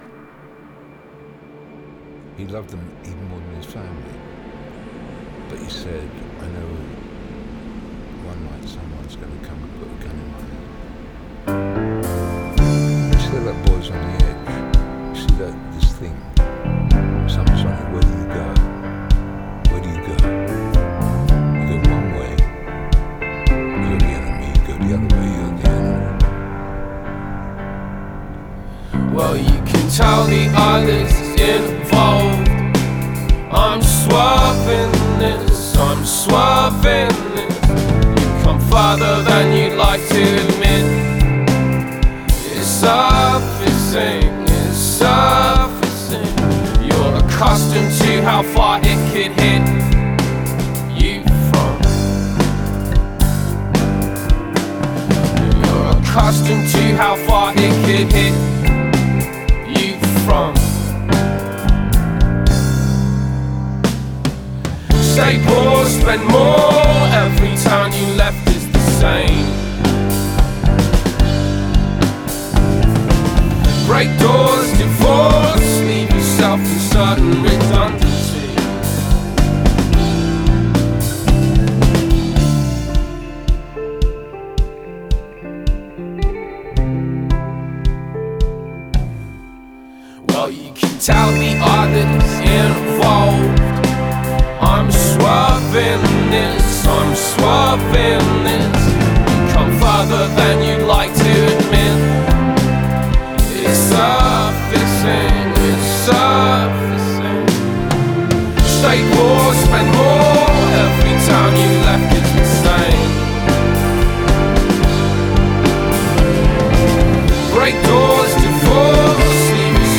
virage électronique